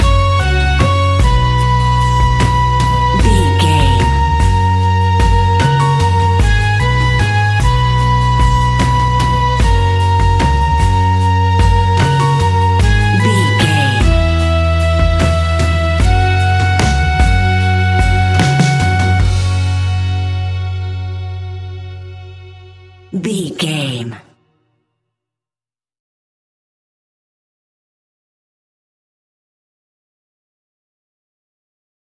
Uplifting
Ionian/Major
G♭
acoustic guitar
mandolin
ukulele
lapsteel
drums
double bass
accordion